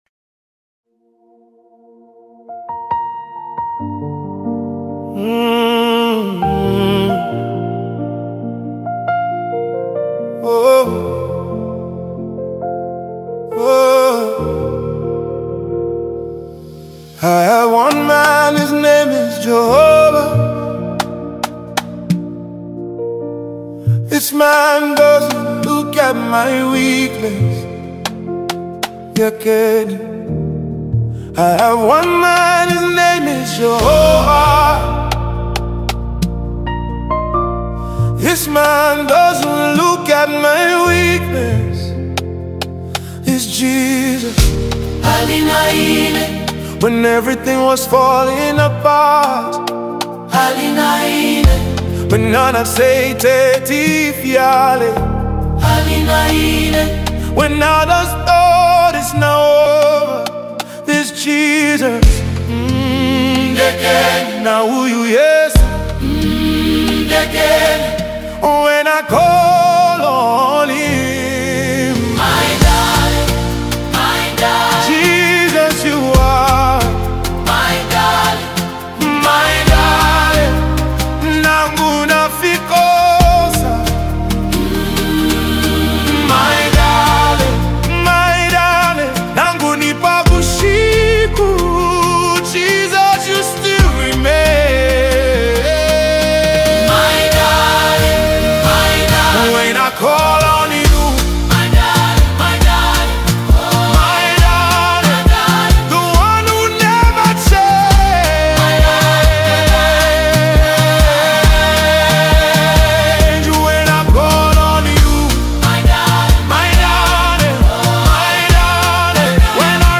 spirit-filled worship anthem